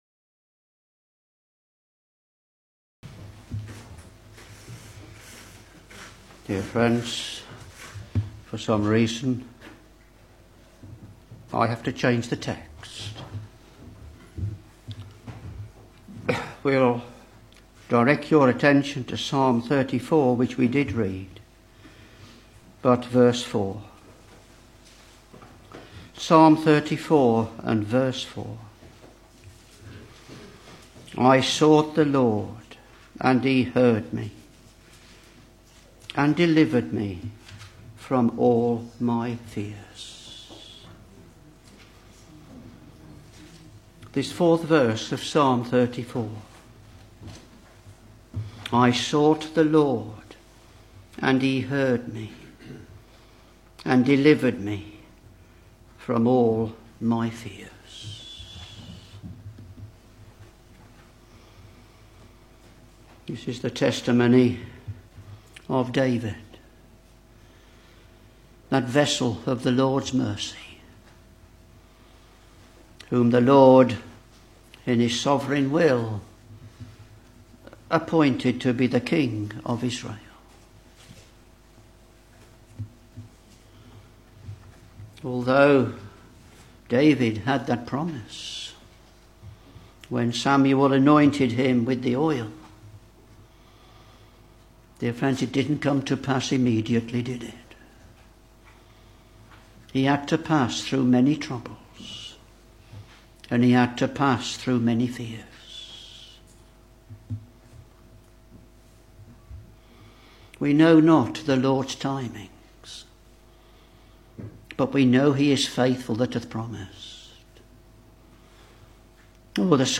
Back to Sermons Psalm 34 v.4 I sought the LORD, and he heard me, and delivered me from all my fears.